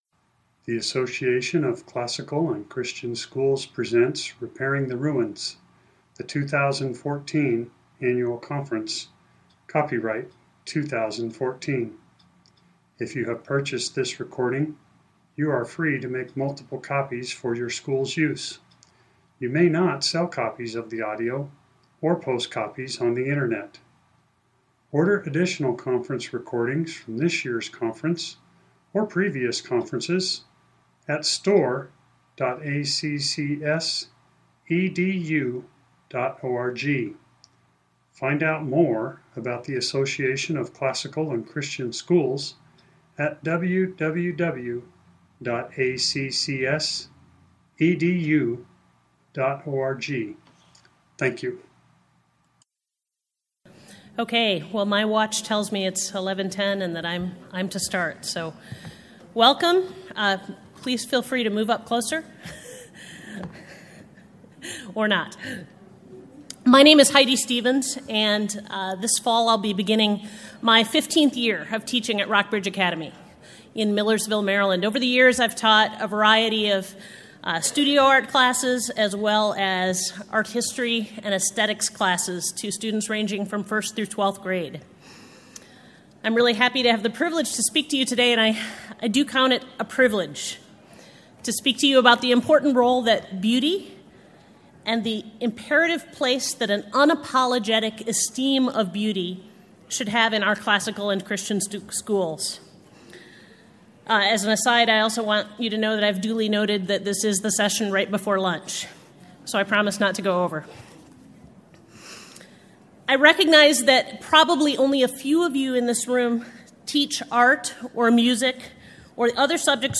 2014 Foundations Talk | 0:54:32 | All Grade Levels, General Classroom
The Association of Classical & Christian Schools presents Repairing the Ruins, the ACCS annual conference, copyright ACCS.